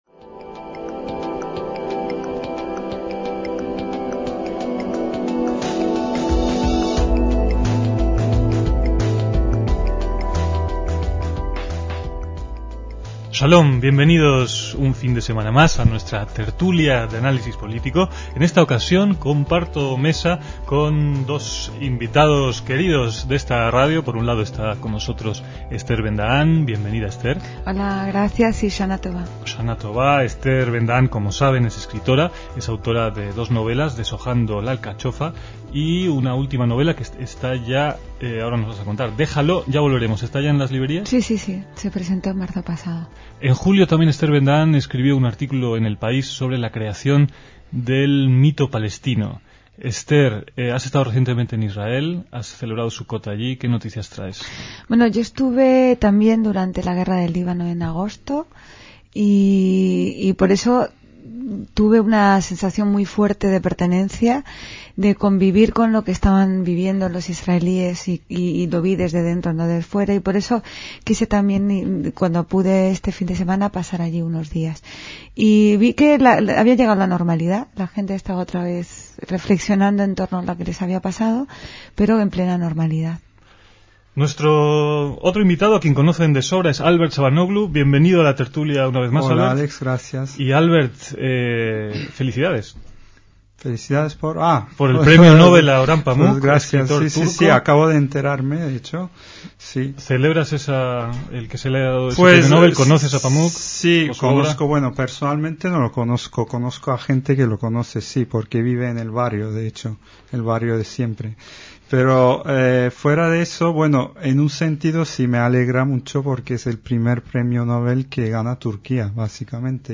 DECÍAMOS AYER (14/10/2006) - En esta tertulia semanal de 2006